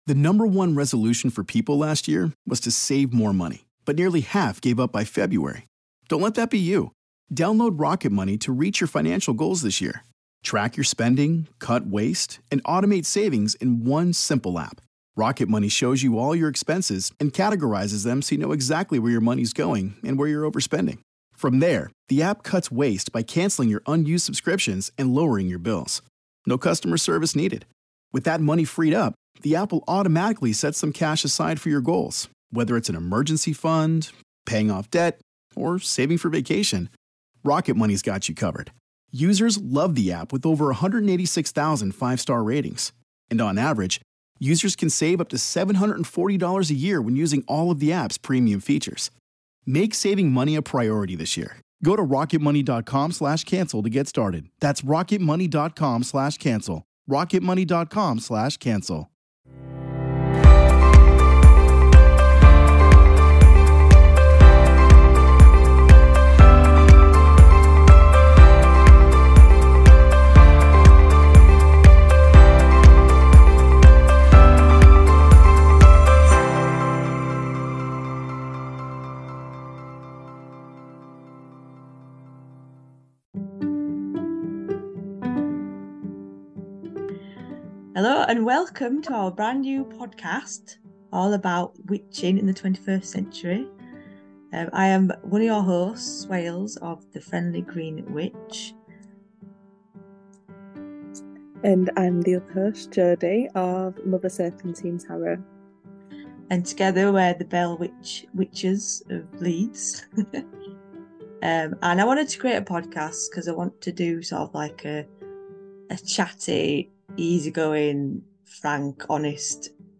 Pour yourself a brew or a glass of mead, come sit with us two northern birds and have a laugh as we go off on tangents.
DISCLAIMER: There is swearing and we don't always stay on topic, but everyone loves an unexpected adventure!